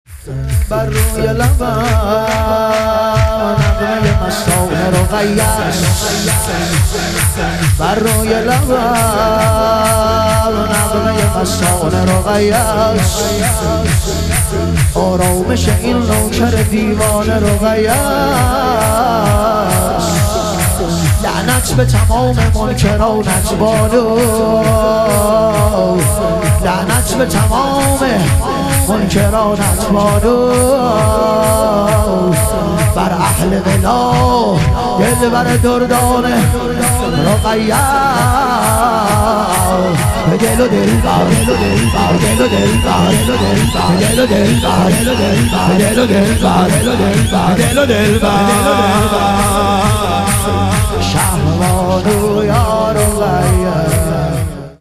شور
شب ظهور وجود مقدس حضرت رقیه علیها سلام